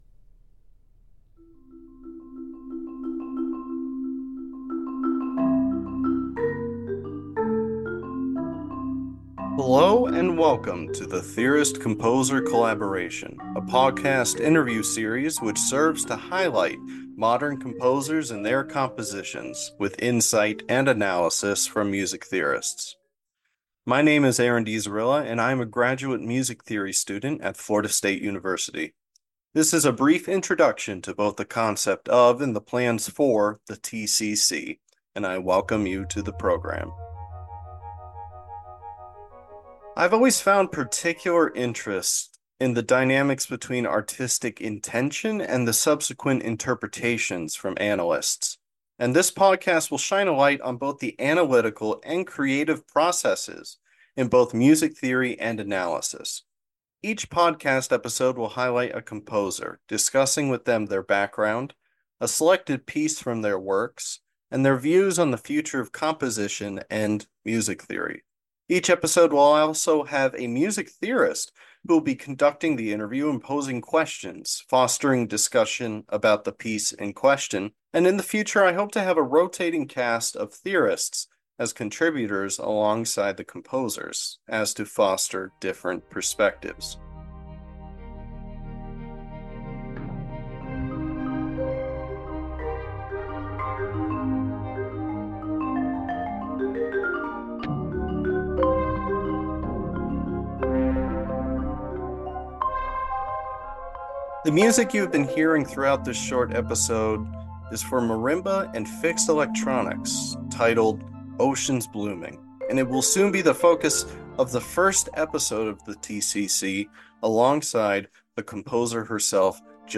The Theorist Composer Collaboration is a podcast interview series highlighting modern composers and music theorists.
Trailer: